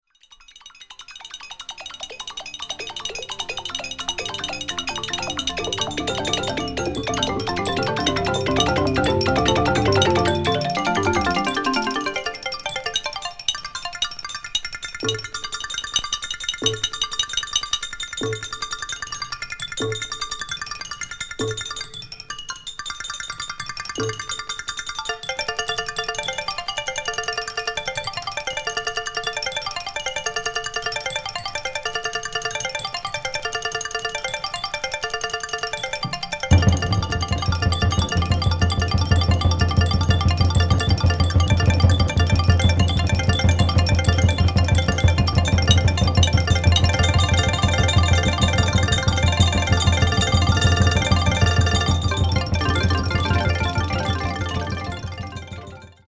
即興　アフロ　電子音